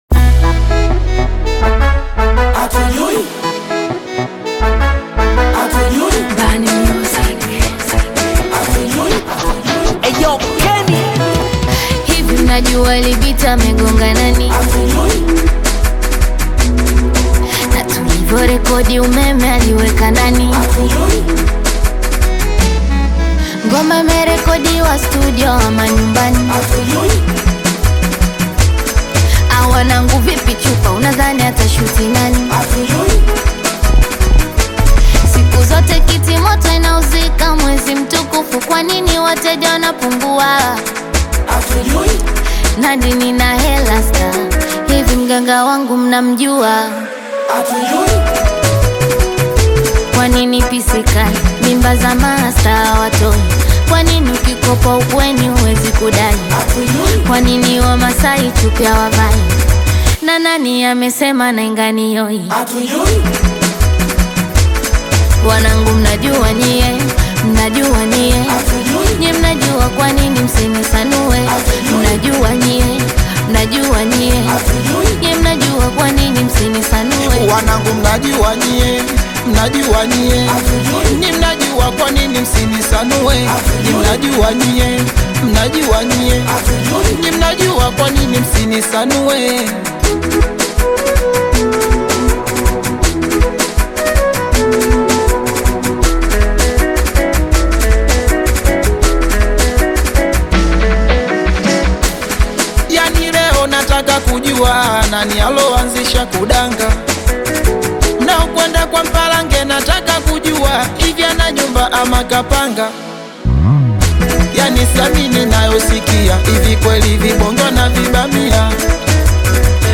bongo flava
Singeli